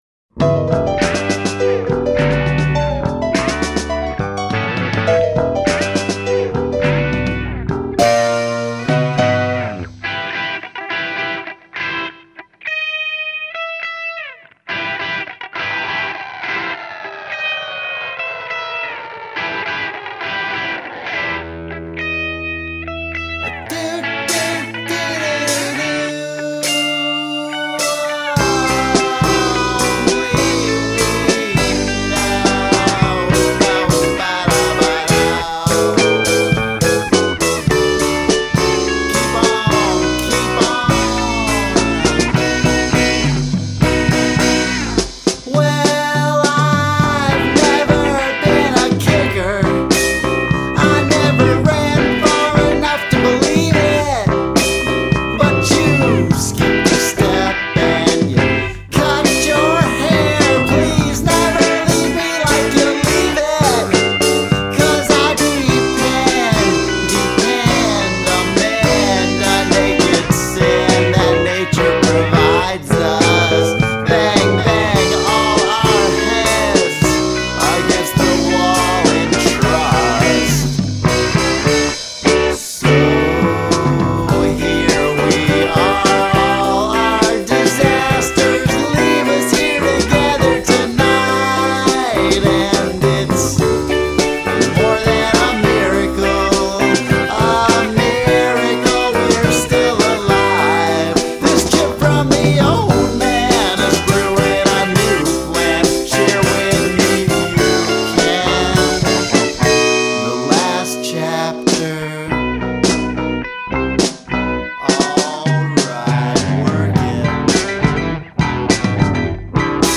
Quirkadelic Rock